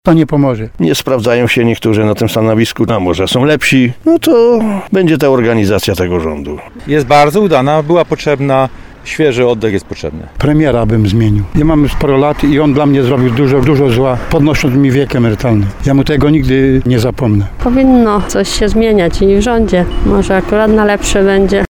Mieszkańcy Tarnowa i okolic tak skomentowali te polityczne zmiany.